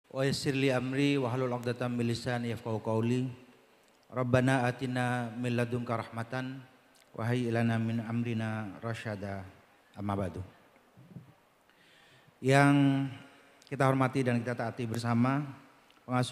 PIDATO REKTOR TAHUN 2024
mp3 dawuh rektor wisuda 2024.mp3